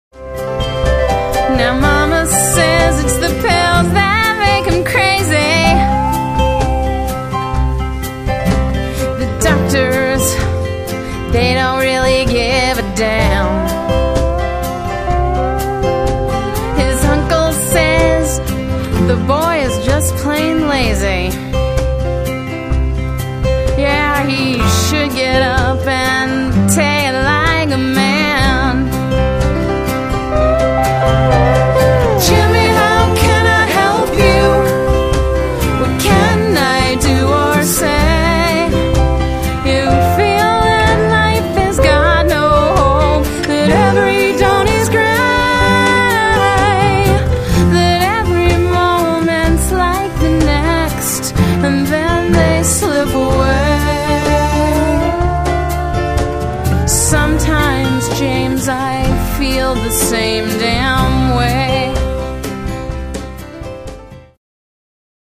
lead vocal, acoustic guitar